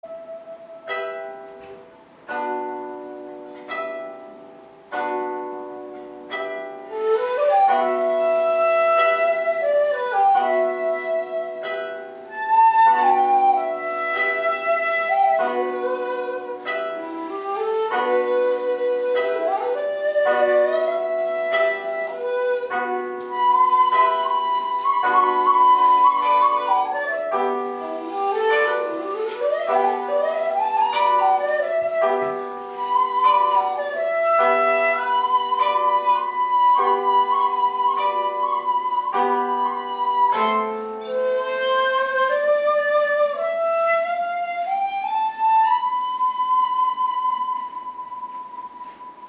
ここでは（５）と似たような旋律が四度下の音で出てきます。